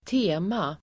Uttal: [²t'e:ma]